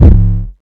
808_TweakN.wav